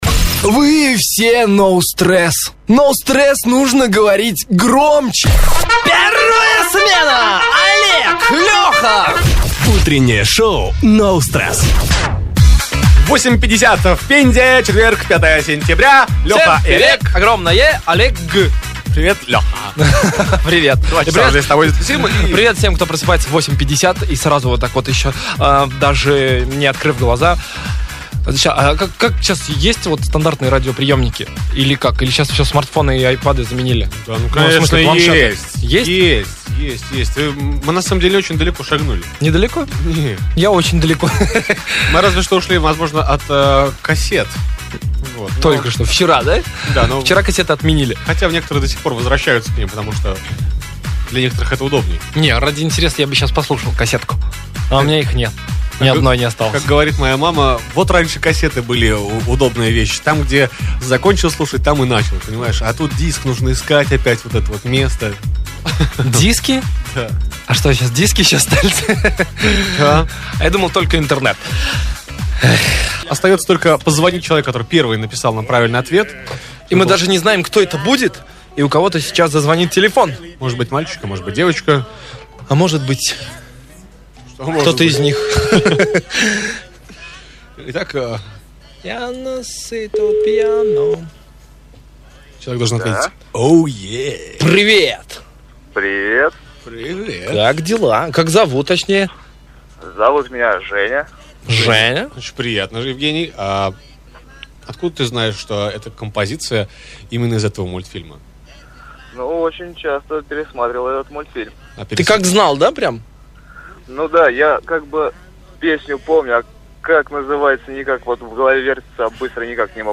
Утреннее шоу No stress (Пенза). ЗАПИСЬ ЭФИРА.
Эфир от 5 сентября 2013 года.